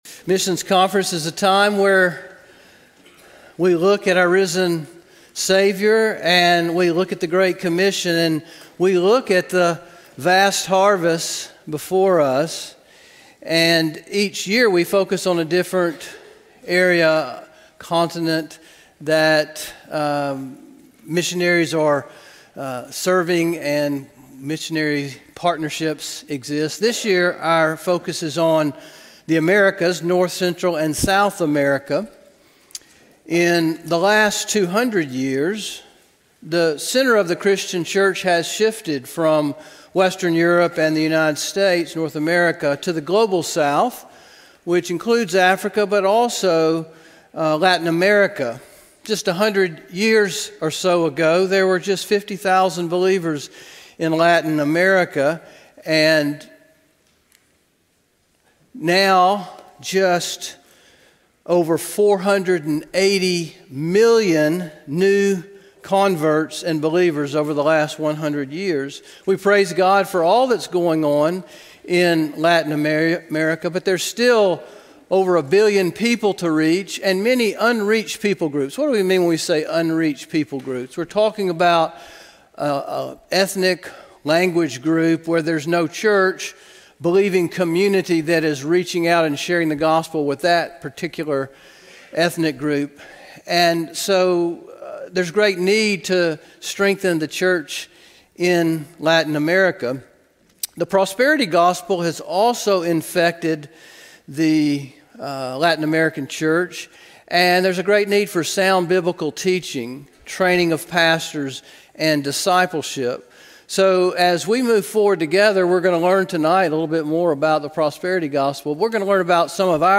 From Series: "2026 Missions Conference"